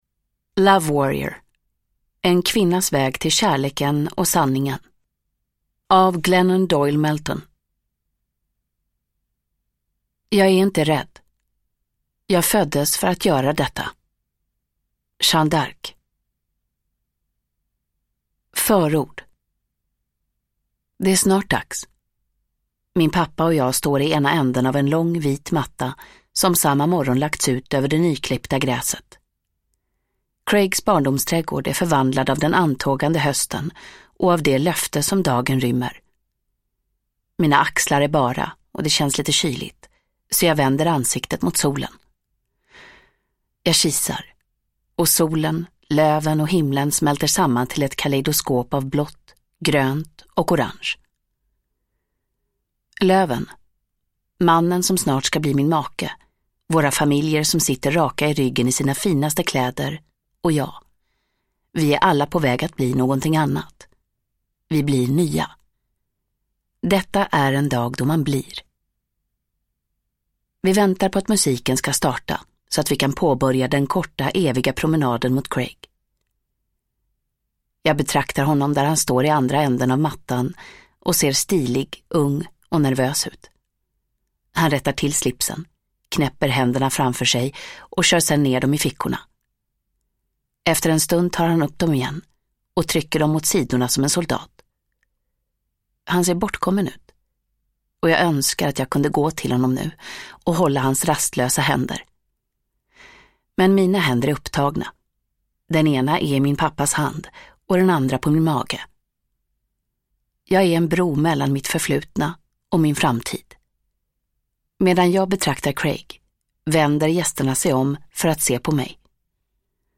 Love Warrior – Ljudbok – Laddas ner